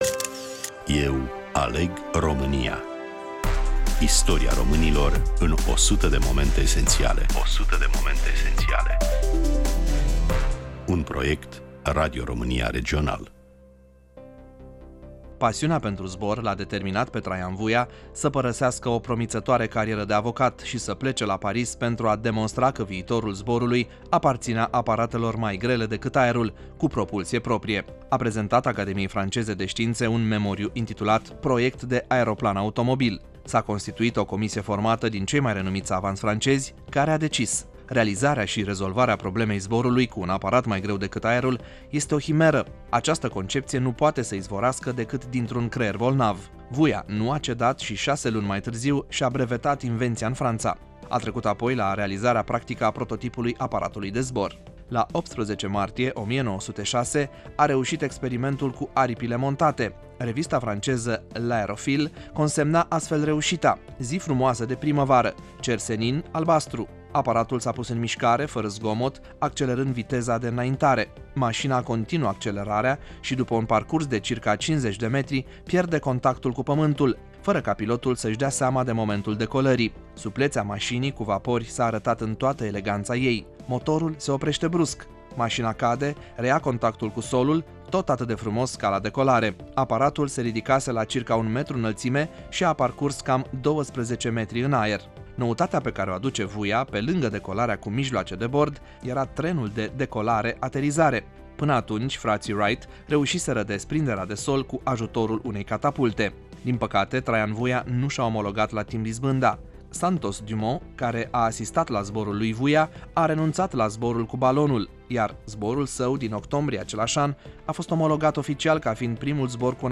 Prezentare, voice over